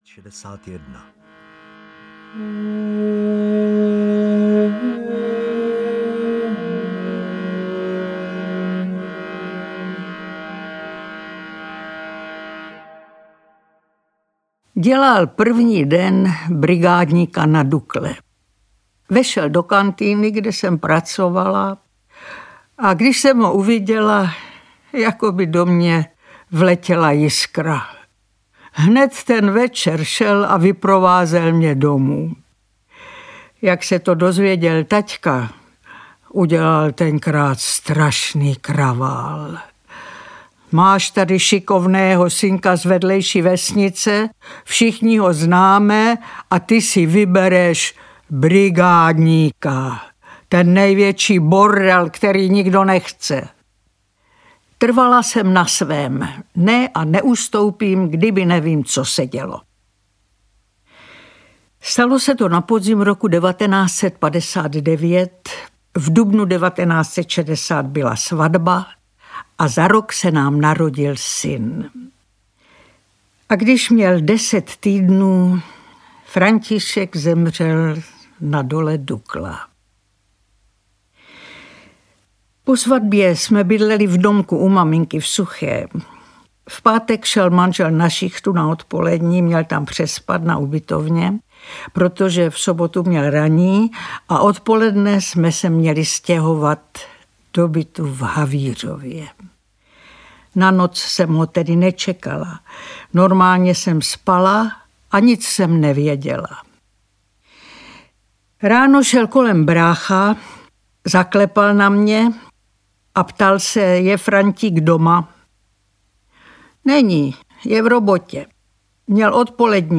Ukázka z knihy
hornicke-vdovy-audiokniha